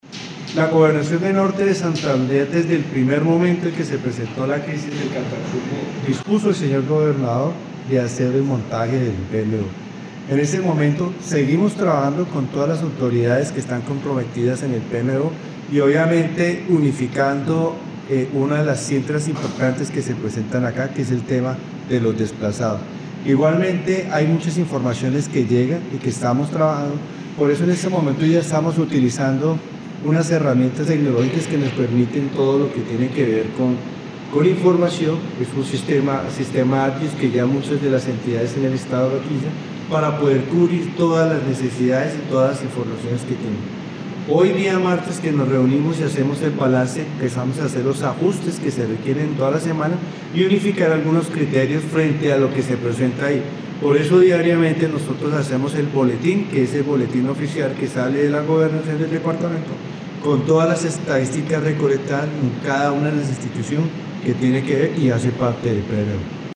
1. Audio de George Quintero, secretario de Seguridad Ciudadana